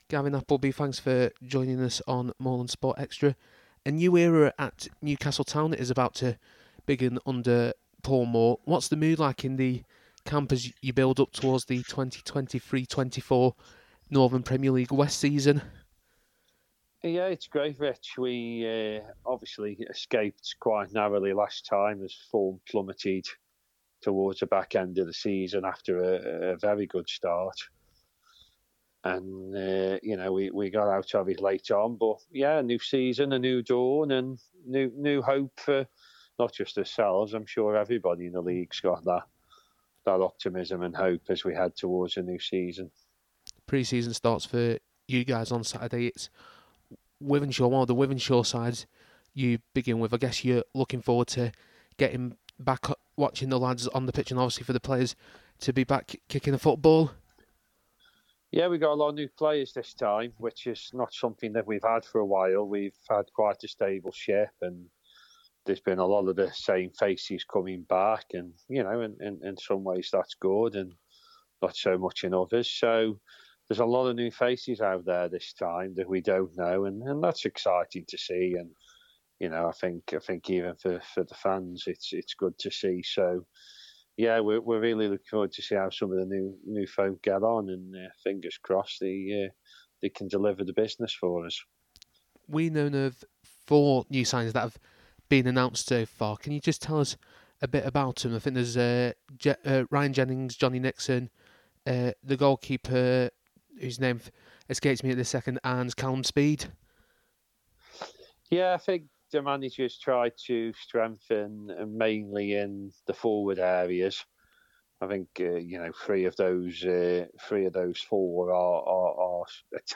In a short new series of interviews, we have caught up with the various chairmen from our local non-league teams to see how preparation for the 2023/24 season is going for their respective clubs.